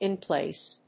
アクセントin pláce